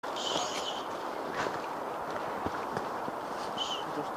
Play, download and share Shh… original sound button!!!!
shh.mp3